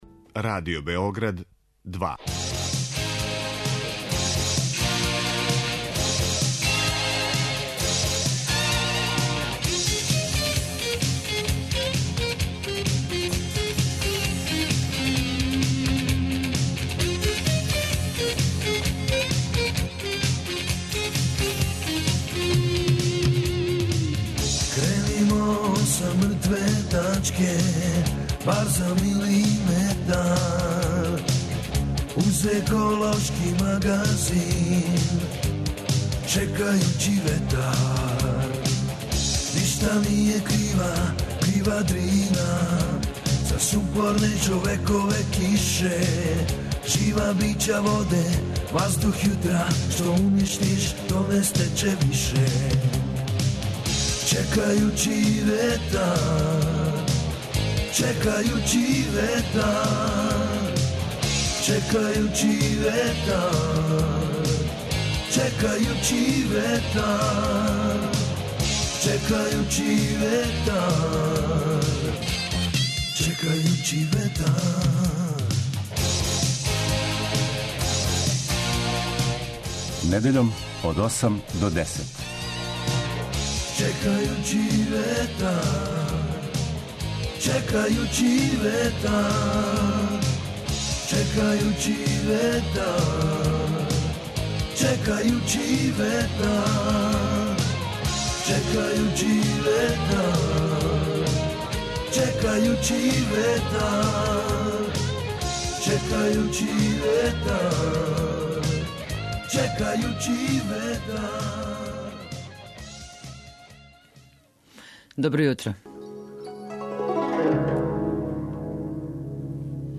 Еколошки магазин који се бави односом човека и животне средине, човека и природе.